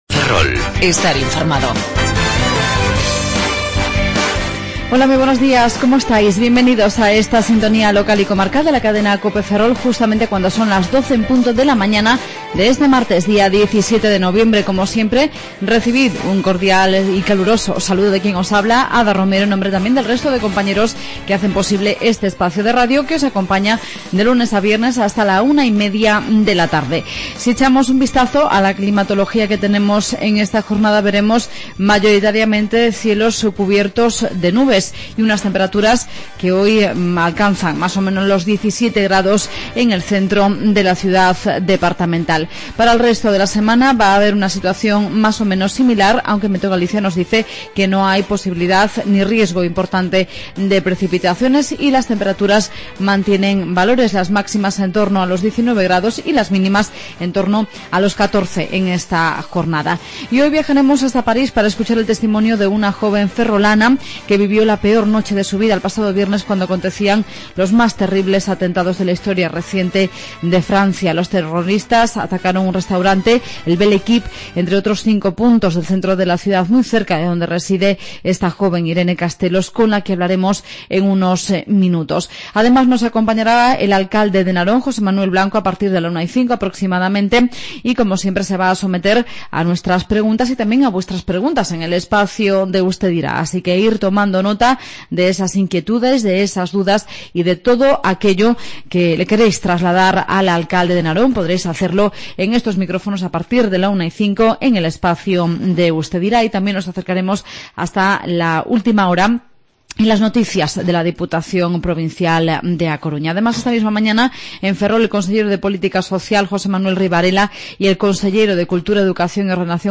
entrevistamos al alcalde de Narón, José Manuel Blanco en el Espacio de Usted Dirá, entre otros asuntos.